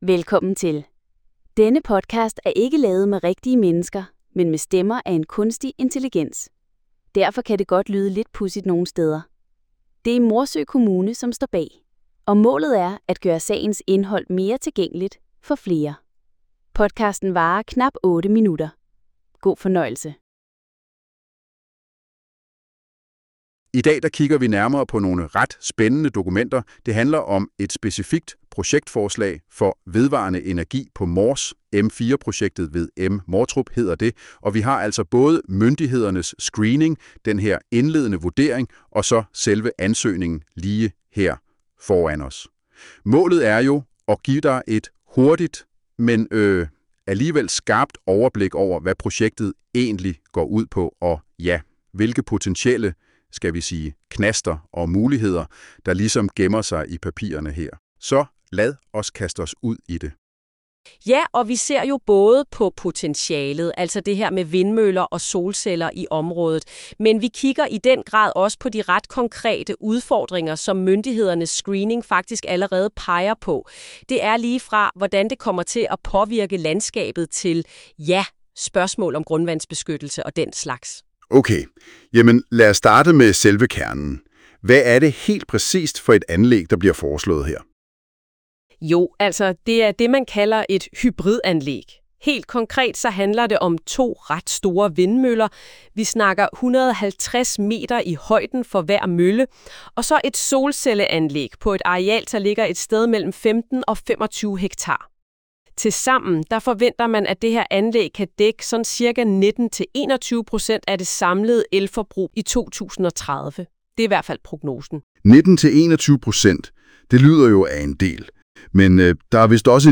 Podcasten er ikke lavet med rigtige mennesker, men med stemmer af en kunstig intelligens. Derfor kan det godt lyde lidt pudsigt nogen steder.